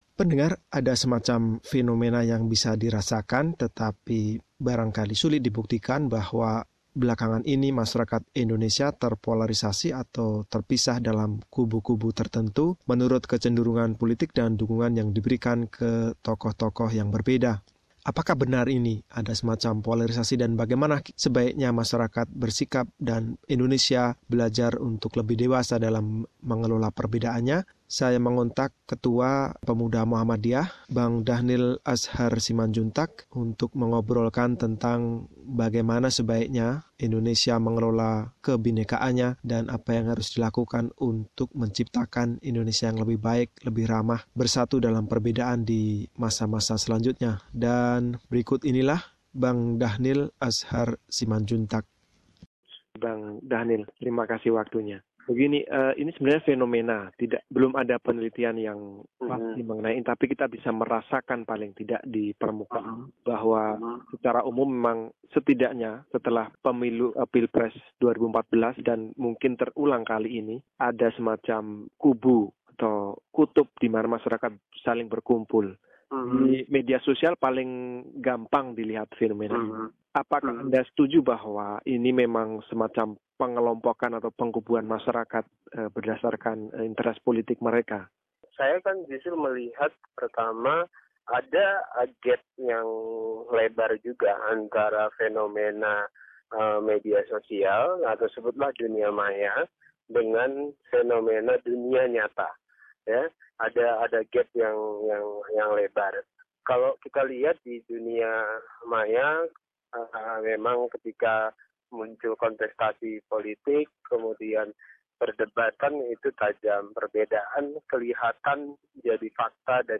Wawancara dengan Ketua Pimpinan Pusat Pemuda Muhammadiyah,Dahnil Azhar Simanjuntak, mengenai polarisasi masyarakat Indonesia.